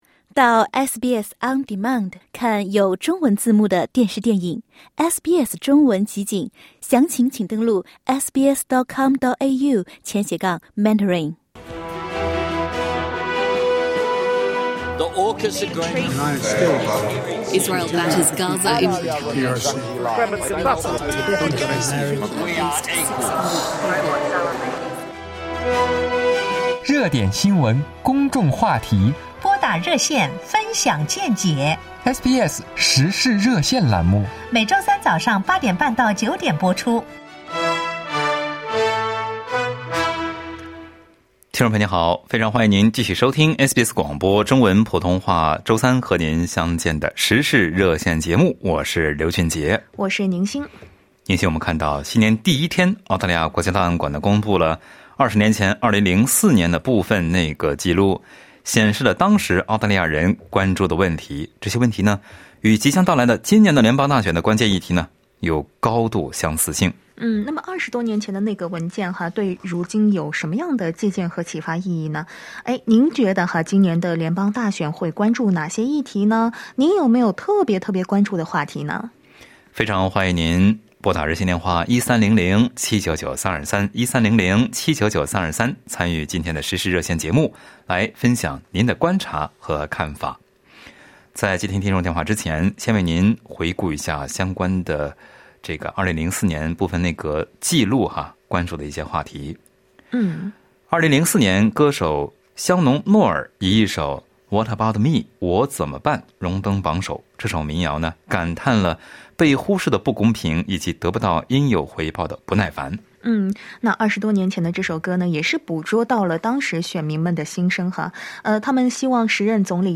今年的联邦选举会关注哪些议题呢？SBS普通话《时事热线》节目的听友们分享了看法。